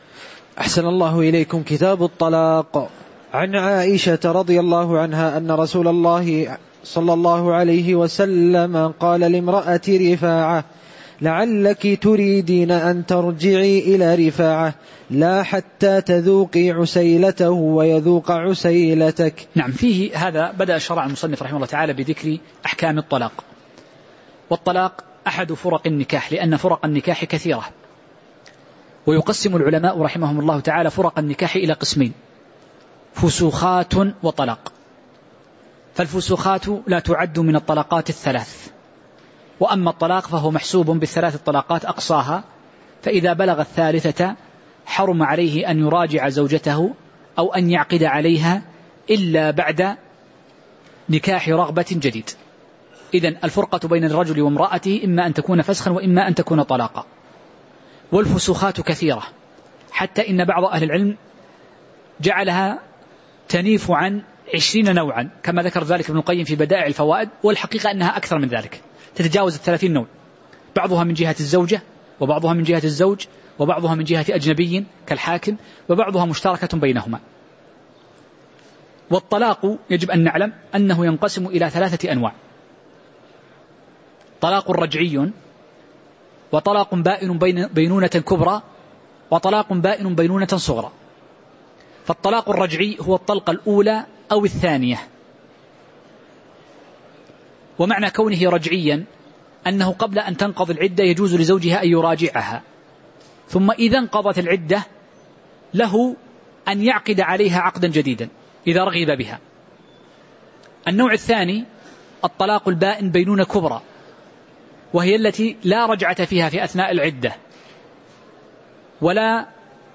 تاريخ النشر ١١ ربيع الأول ١٤٤١ هـ المكان: المسجد النبوي الشيخ